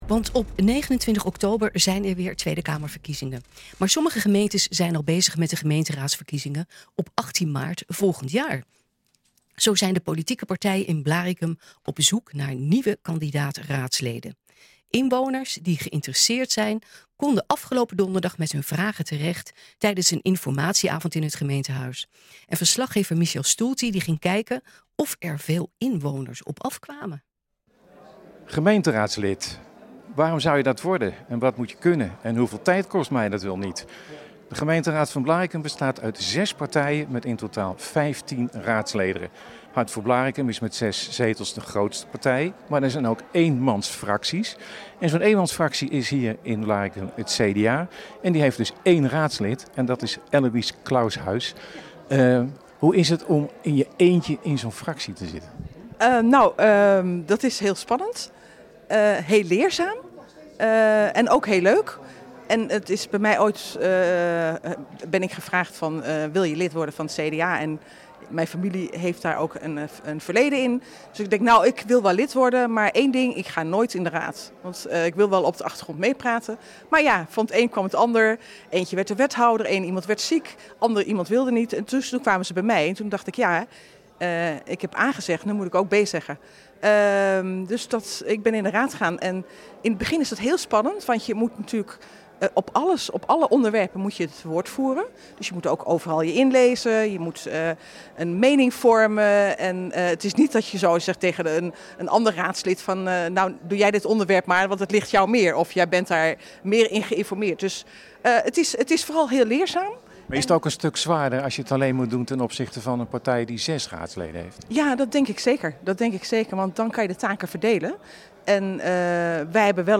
Inwoners die geïnteresseerd zijn konden afgelopen donderdag met hun vragen terecht tijdens een informatieavond in het Gemeentehuis.